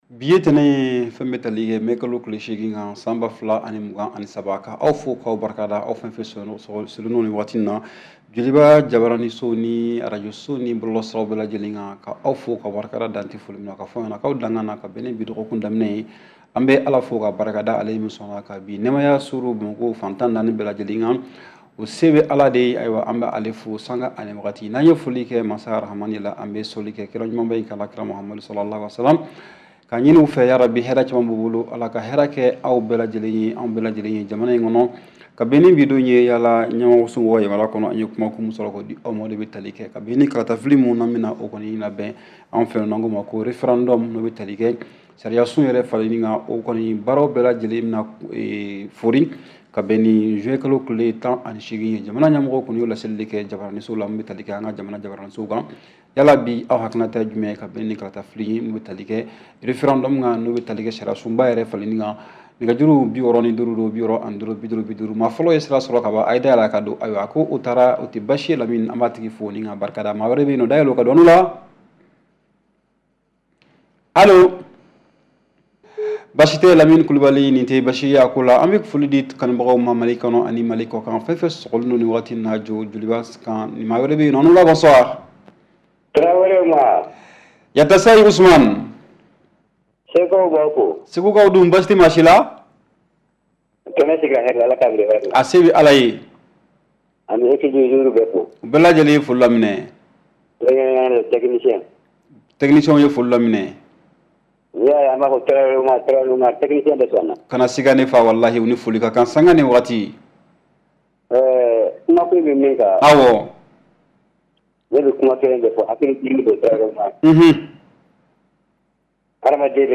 REPLAY 08/05 – « DIS ! » Le Débat Interactif du Soir
Appelle-nous et donne ton point de vue sur une question d’actualité (politique, économique, culturelle, religieuse, etc.). Pas de sujets tabous : arguments, contre-arguments !